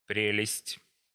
1. ^ from Church Slavonic: пре́лесть, romanized: prélestʹ, IPA: [ˈprʲelʲɪsʲtʲ]